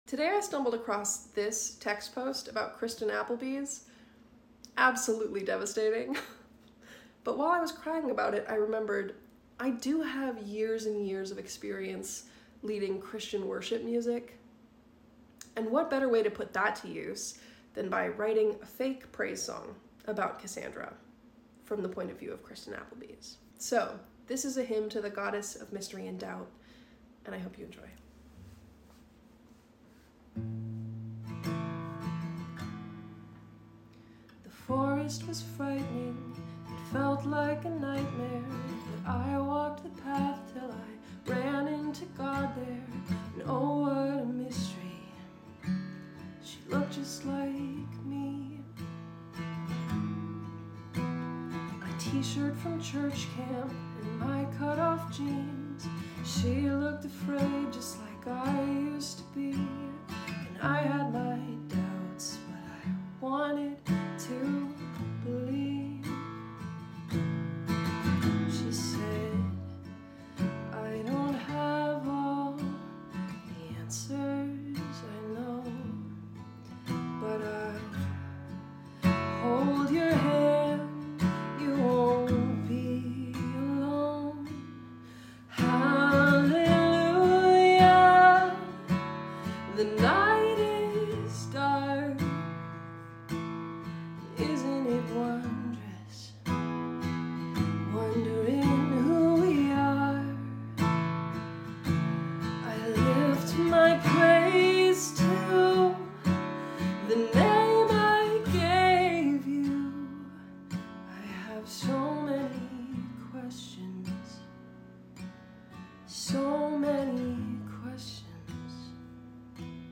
had worship music, it might sound like this